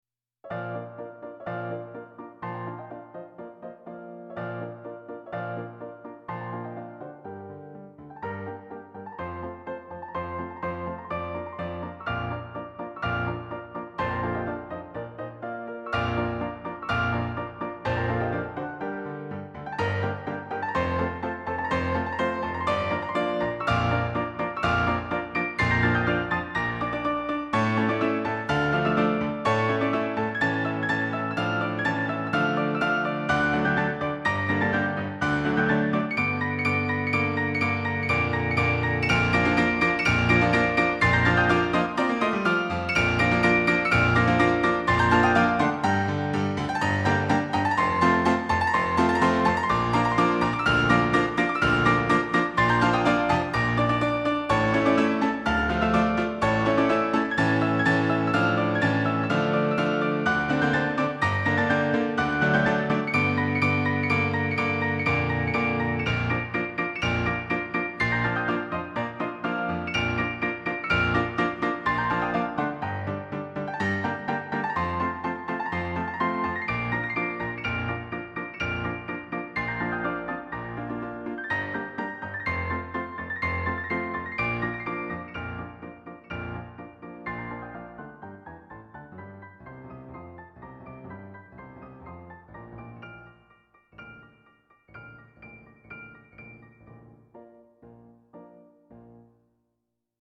ピアノ